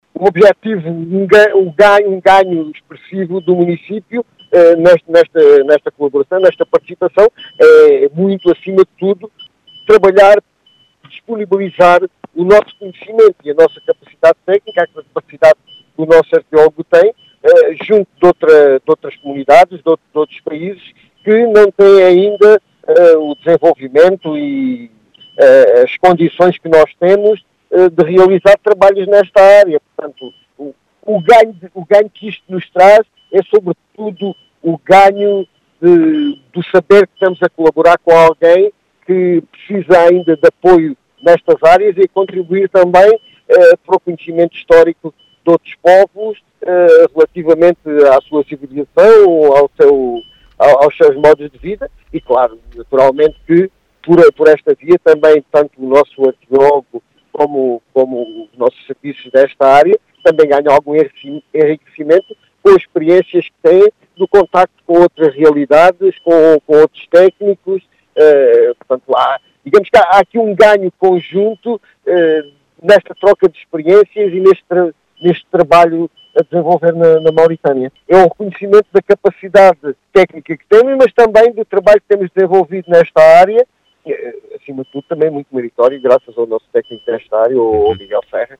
As explicações são de João Efigénio Palma, presidente da Câmara Municipal de Serpa.
Joao-Efigenio-Palma.mp3